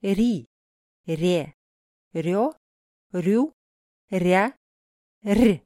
🔊 Hörprobe: Weiches Р in Silben РИ [rʲi] РЕ [rʲe] РЁ [rʲo] РЮ [rʲu] РЯ [rʲa] РЬ [rʲ]
r-russisch-weich-silben.mp3